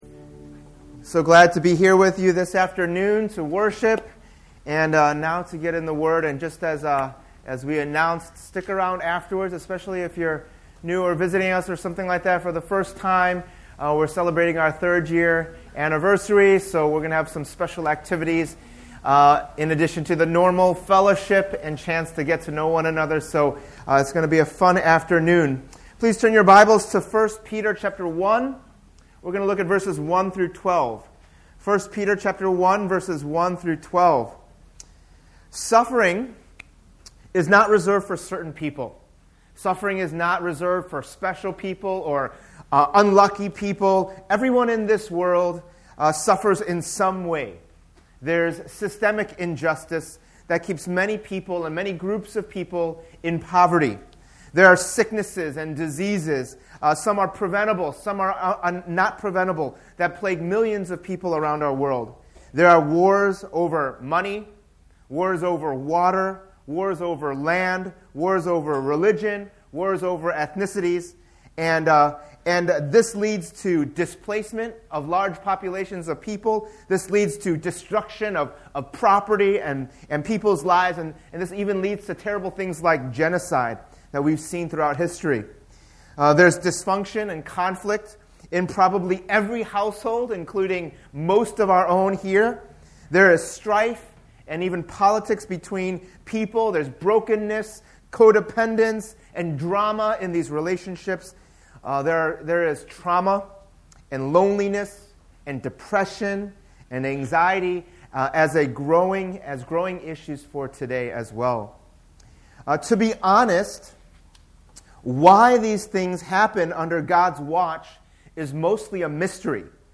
In this sermon series, we’ll be looking through Apostle Peter’s First Letter to different churches in the first century amidst a time of great suffering and persecution, and we’ll see different aspects of what God calls us to as disciples of Jesus Christ: 1: Experiencing Salvation 2: Growing Up Into Salvation 3: Knowing Who We Are Together 4: Living as Servants 5: Living as Wives & Husbands 6: Suffering for Doing Good 7: Glorifying God in Everything 8: Suffering as a Christian 9: Standing Firm as the Church